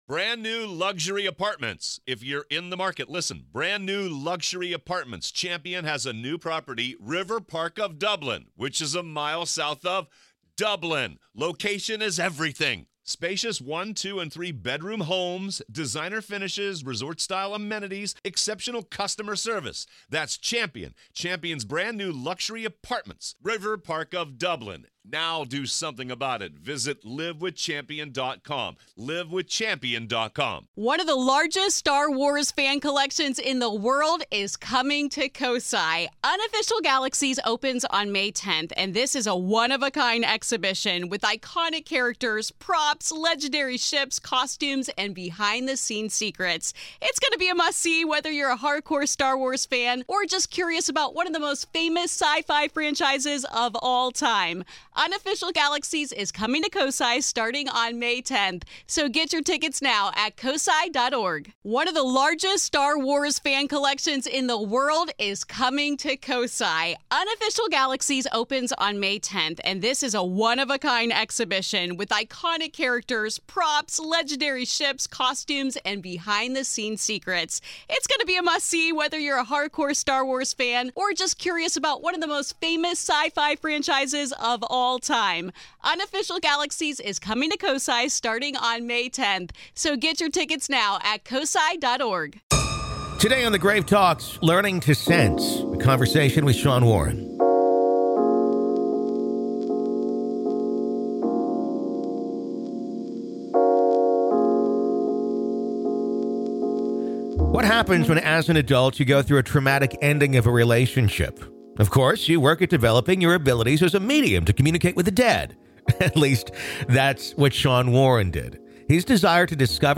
LISTEN HERE In part two of our interview